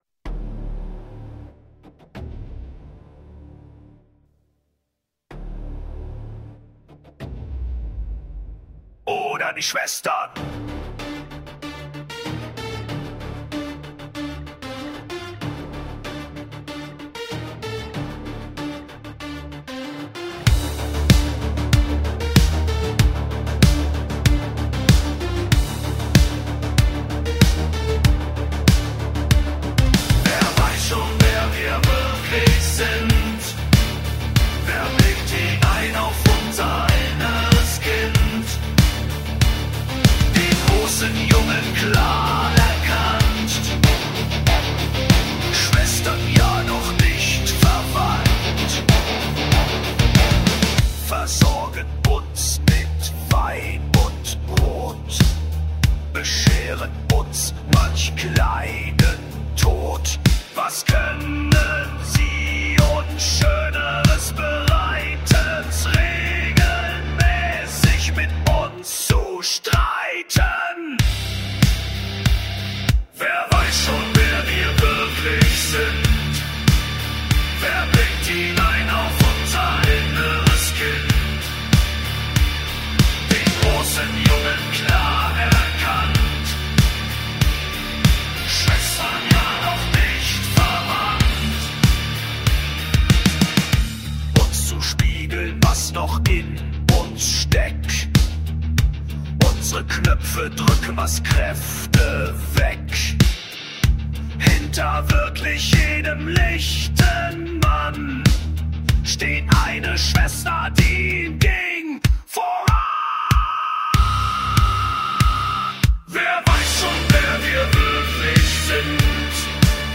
Neue Deutsche Härte